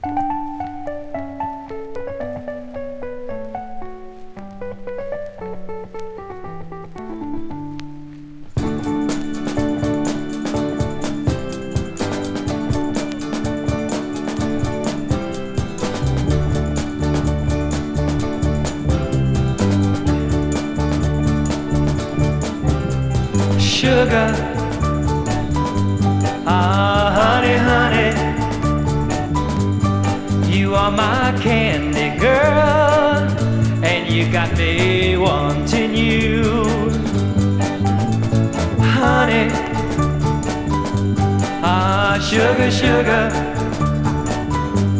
Rock, Pop　USA　12inchレコード　33rpm　Mono